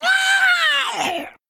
silverhandmurloc_og_006a_attack.mp3